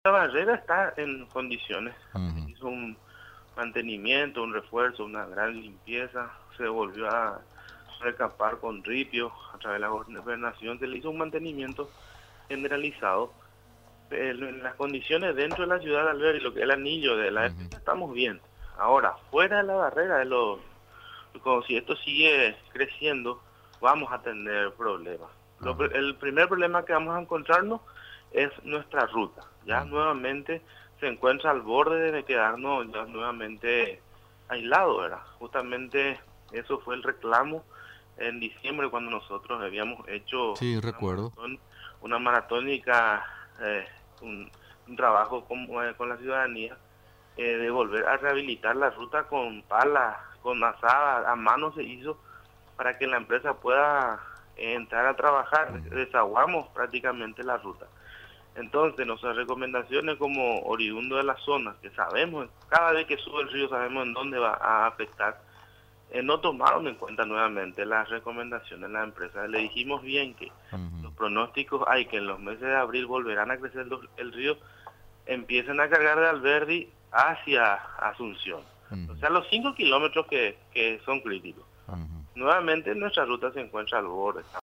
La ciudad de Alberdi, departamento de Ñeembucú, está a punto de quedar aislada, informó este martes el presidente de la Junta Municipal, Juan Pablo Bogarín.